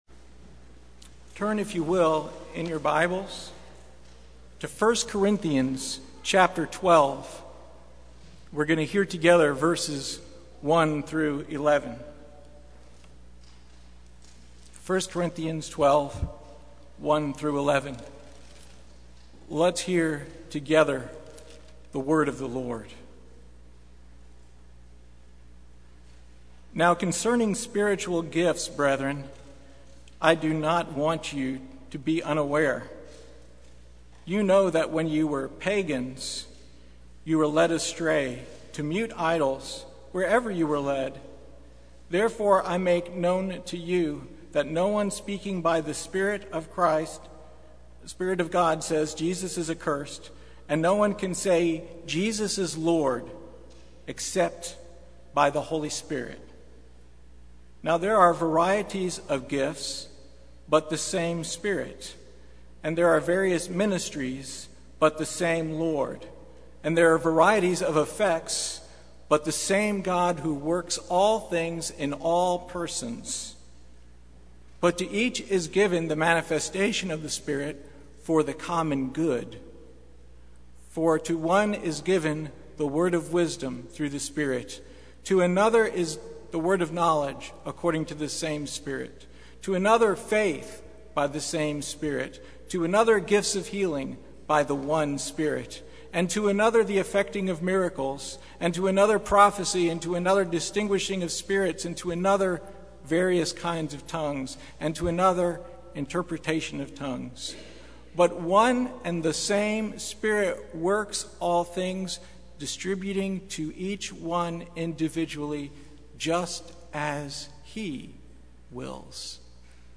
Passage: 1 Corinthians 12:1-11 Service Type: Sunday Morning